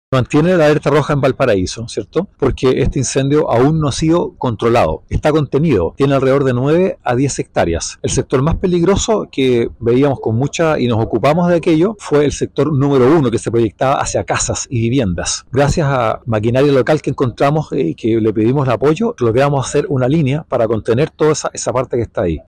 El director regional de Conaf, Leonardo Moder, sostuvo que el incendio aún no ha sido controlado, precisando que el sector más peligroso es el que se proyectaba hacia viviendas, lo que gracias al trabajo de las distintas entidades se logró contener.